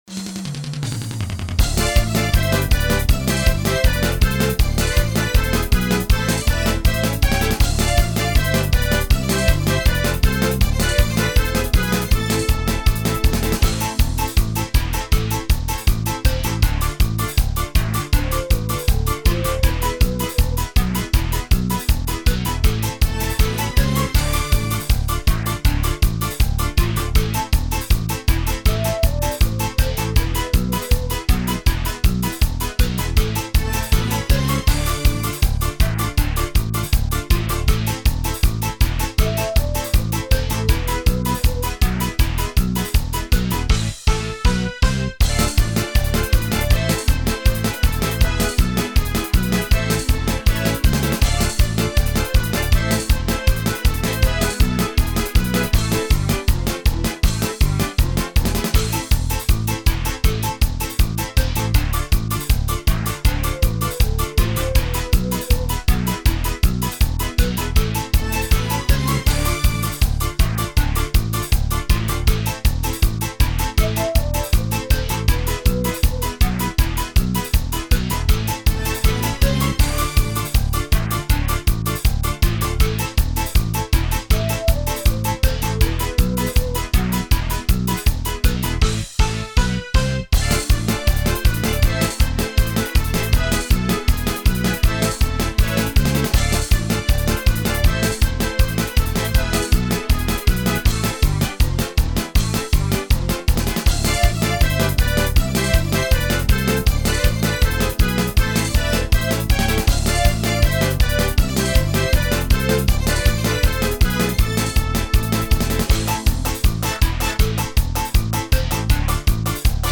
минусовка версия 14231